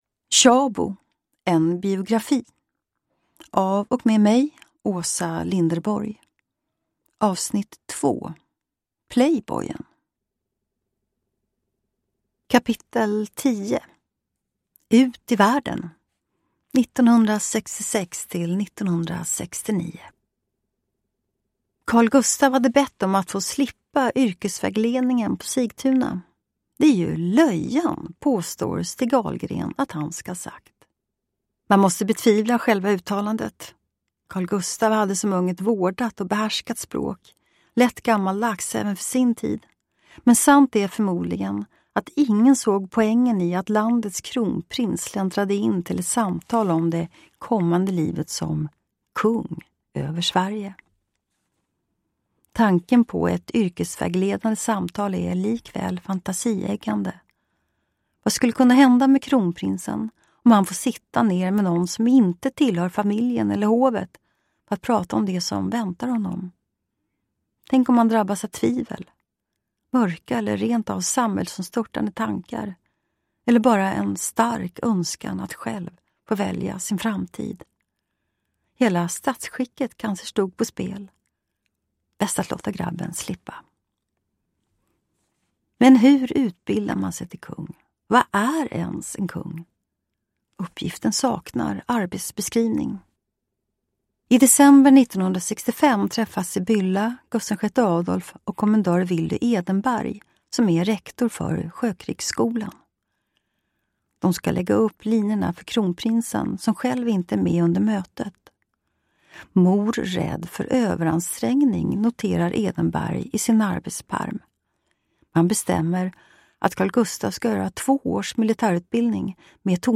Uppläsare: Åsa Linderborg
Ljudbok